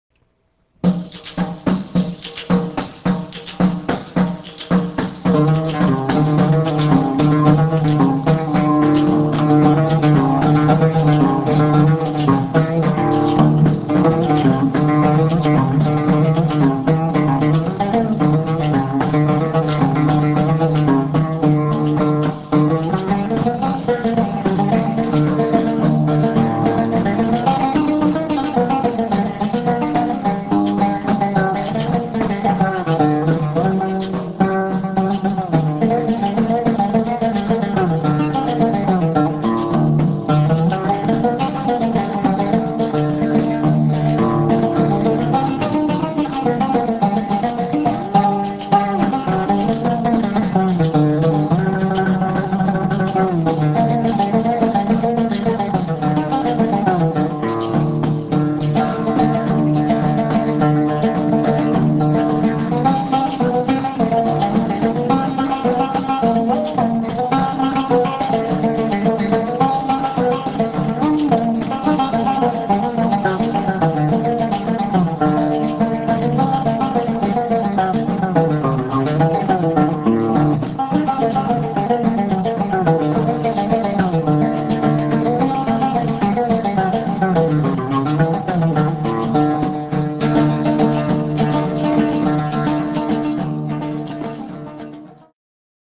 oud (Middle Eastern lute) and nay (cane flute)